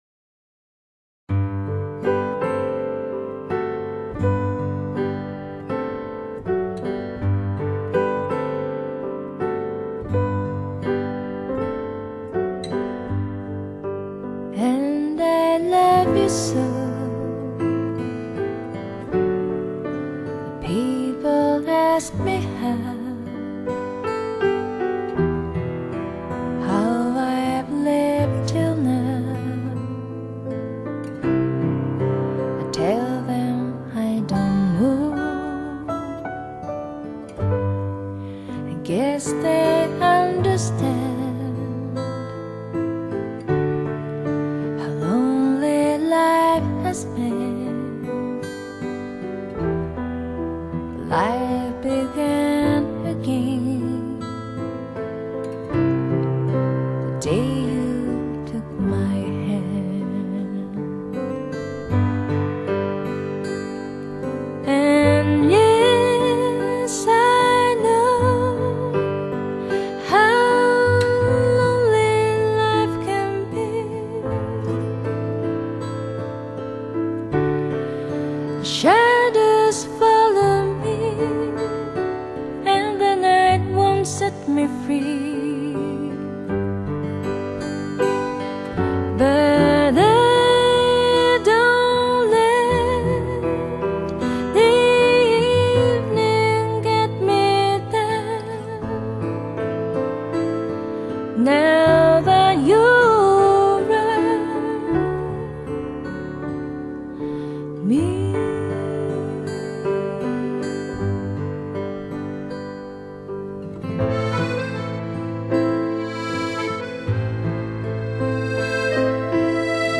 透明系天籟美聲